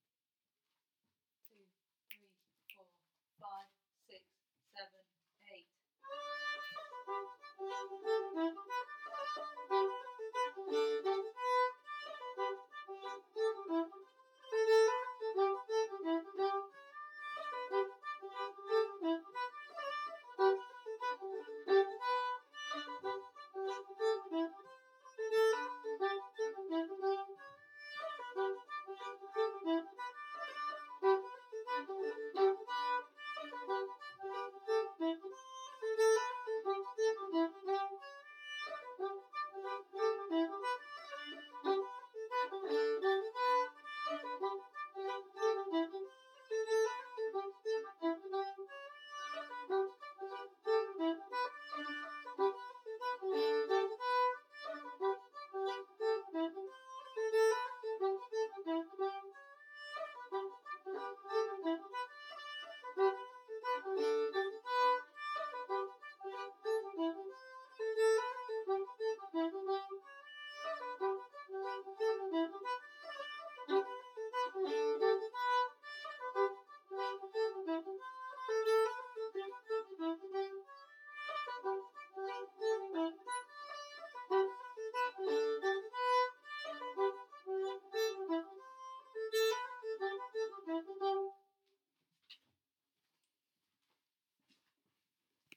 B Part Only